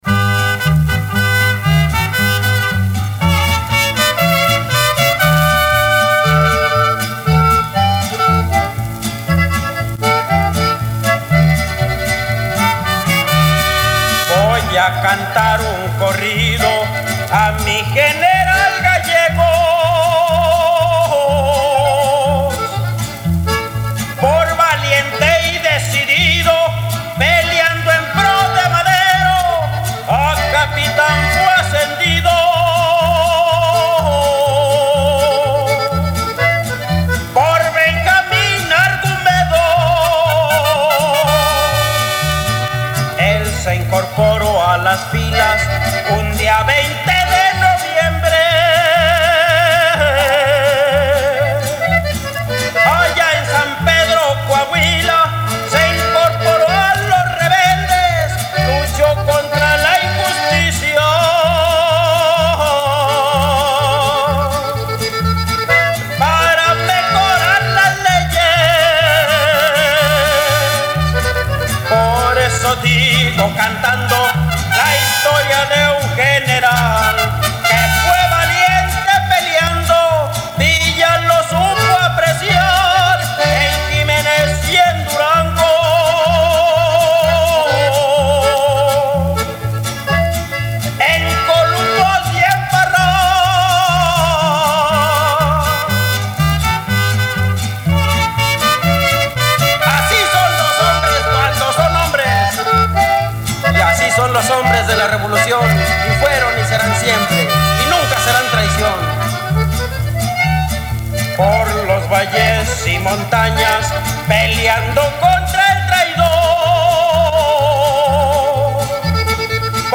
musica folklorica real